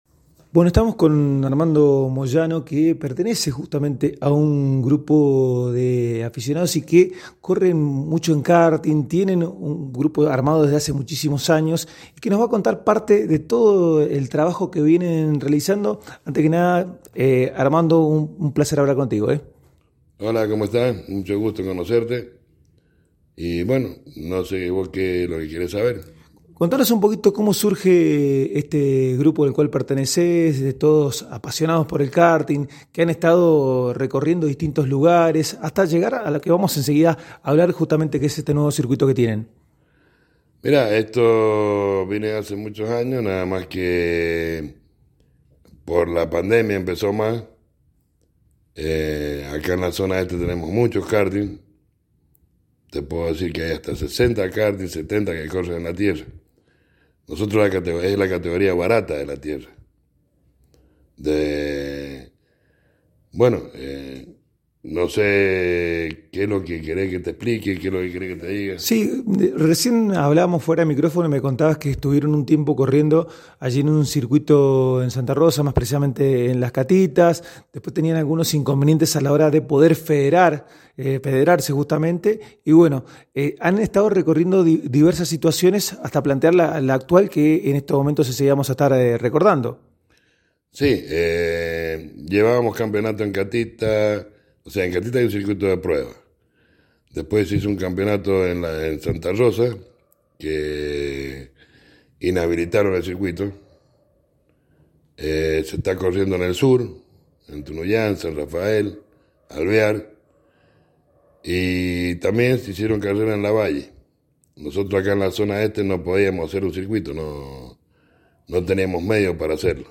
A continuación te dejamos el audio de la nota completa realizada en el magazine de FM 103.5 General San Martín: